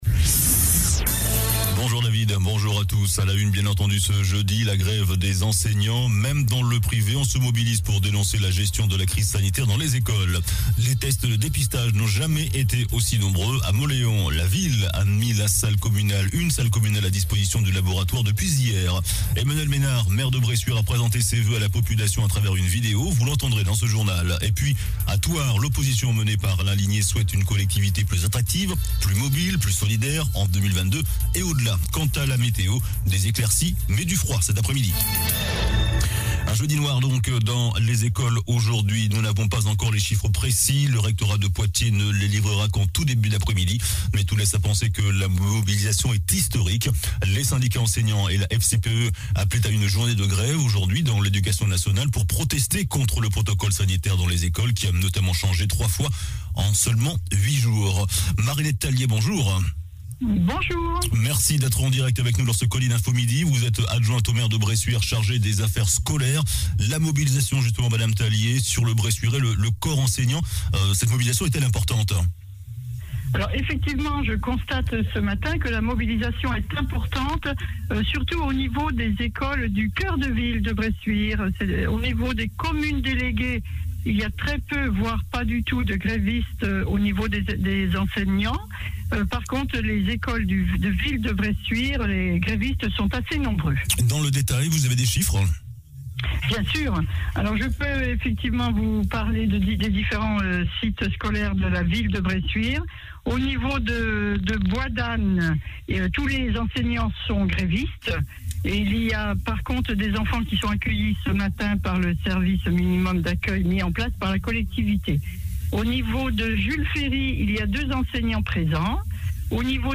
JOURNAL DU JEUDI 13 JANVIER ( MIDI )